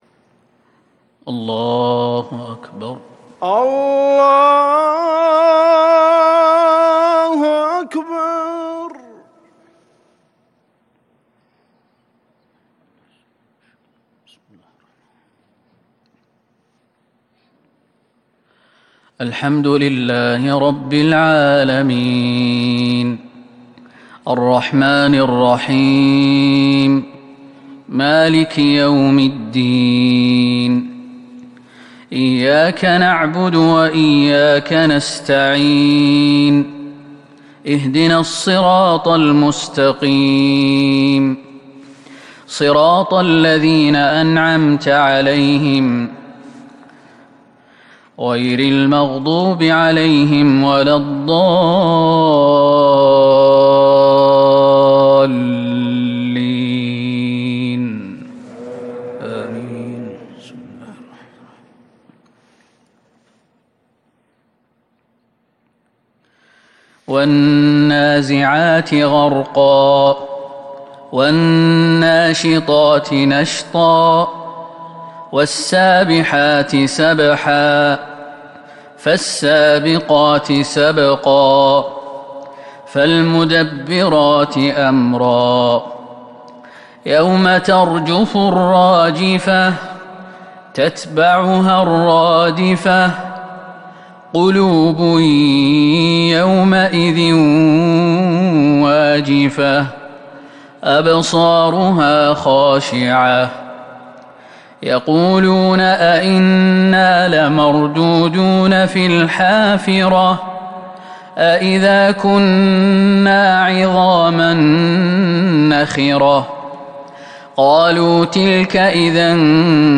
صلاة الفجر من سورتي النازعات والضحى السبت 7 ربيع الأول 1442ه‍ـ | Fajr prayer from Surat An-Naziat and Surat Ad-Duha 24/10/2020 > 1442 🕌 > الفروض - تلاوات الحرمين